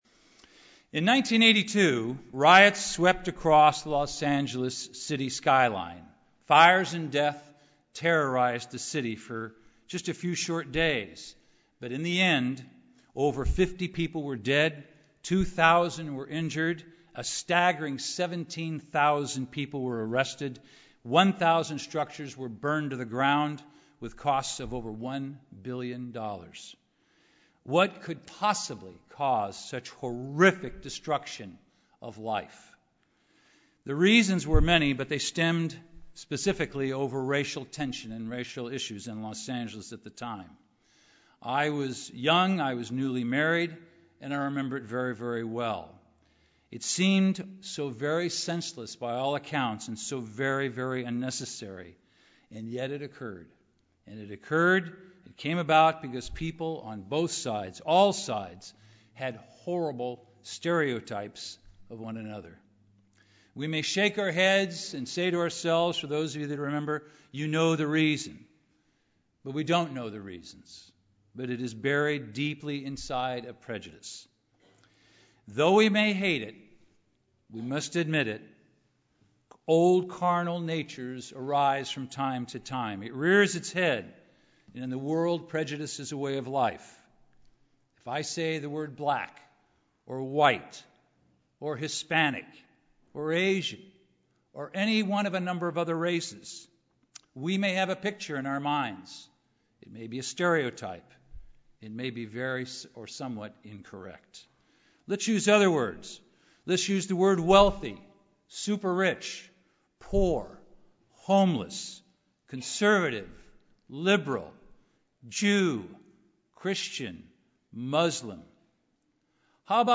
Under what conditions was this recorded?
Given in Los Angeles, CA